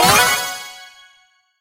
enemy_gadget_button_01.ogg